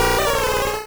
Cri de Tygnon dans Pokémon Rouge et Bleu.